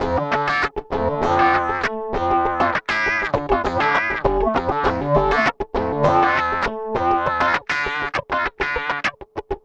OOZIE WAH -L.wav